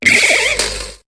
Cri de Mygavolt dans Pokémon HOME.